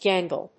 音節gan・gle 発音記号・読み方
/gˈæŋgl(米国英語)/